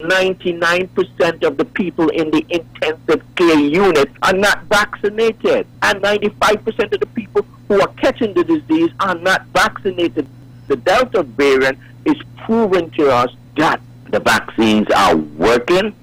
During an interview